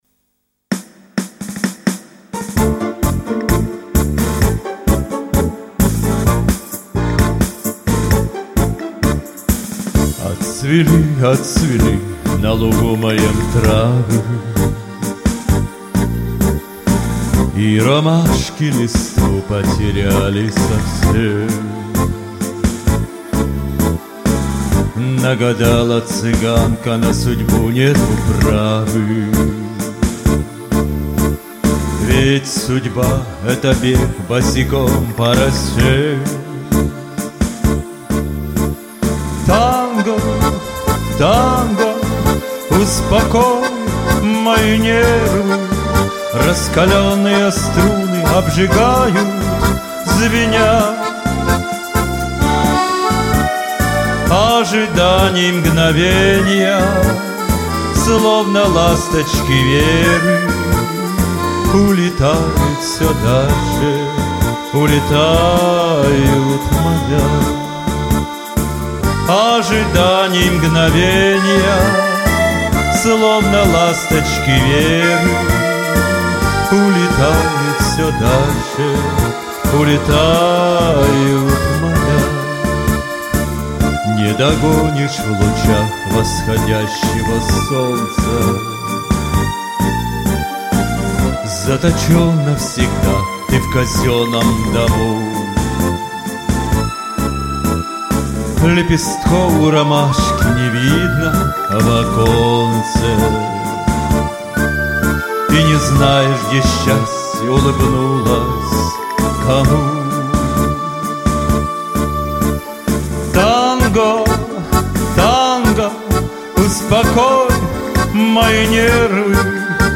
• Жанр: Романс